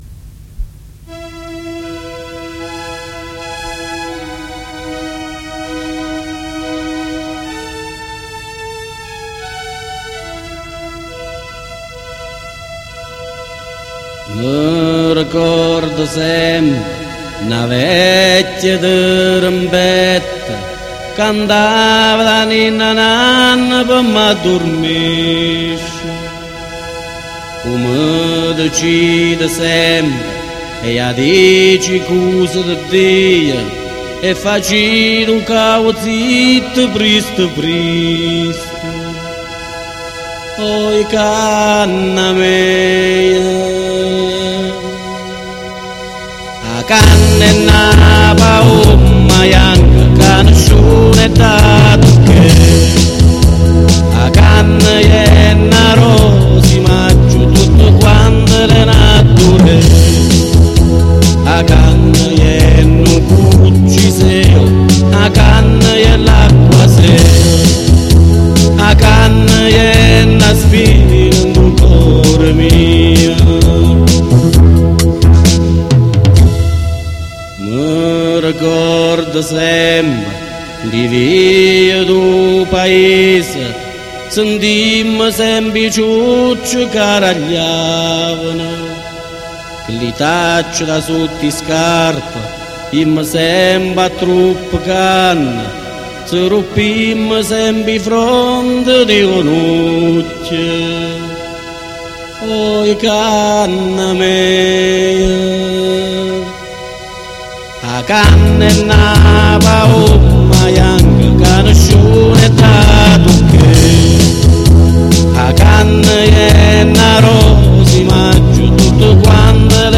in dialetto cannese